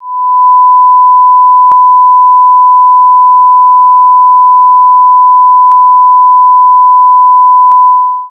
TEST TONE.wav